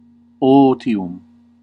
Ääntäminen
US : IPA : [ˈkwaɪ.ət]